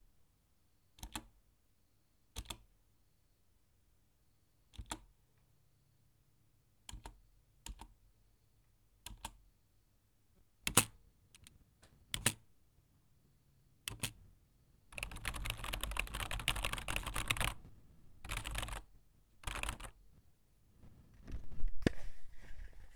Key board
computer key key-board keyboard keys keystroke laptop OWI sound effect free sound royalty free Memes